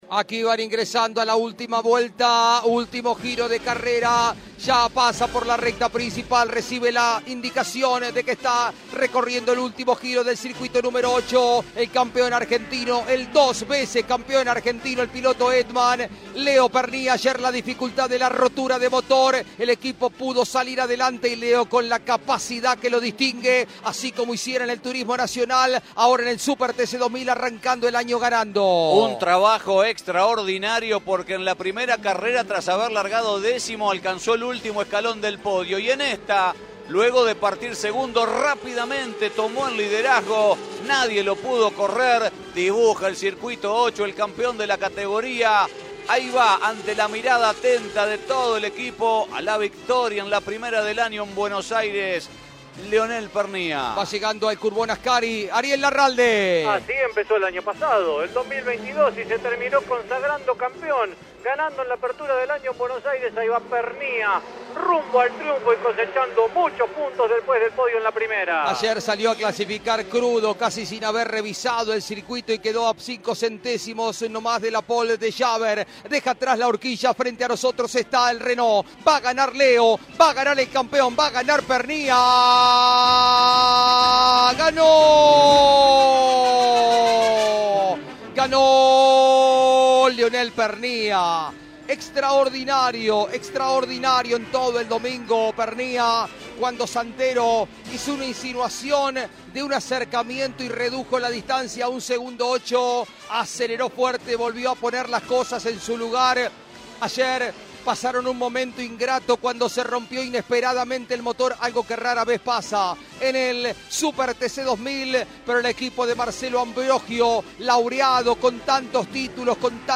Relato